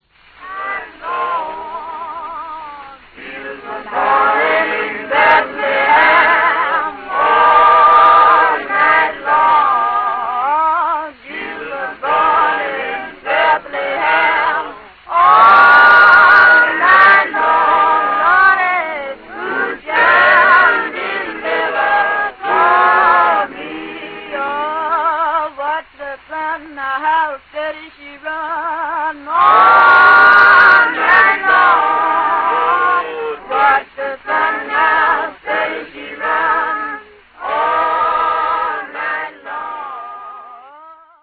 The other recording is closer to the indigenous sea island tradition; although the usual local trademarks, such as handclapping, body percussion and overlapping vocals are not much in evidence.
Earl Archives BD-619.7  Whatever; it makes a joyful noise, and the notes mention the possibility that it may have been used as a Christmas or Easter hymn.